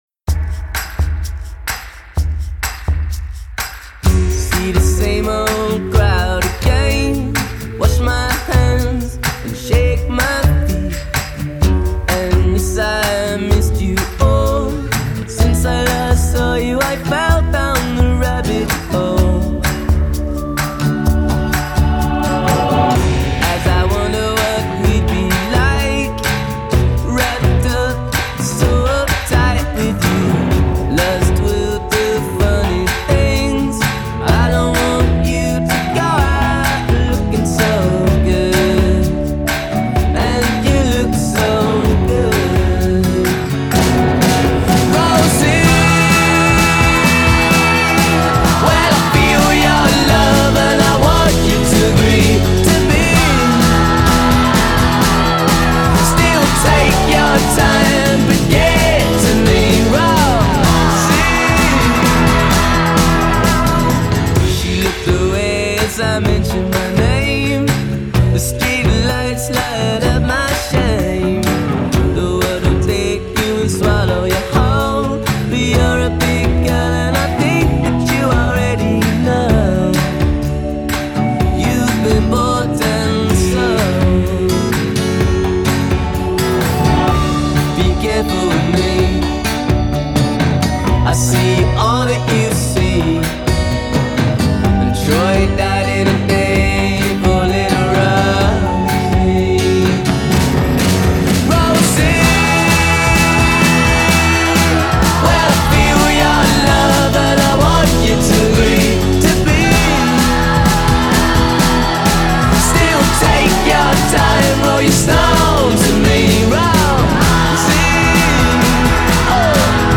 Style: Indie rock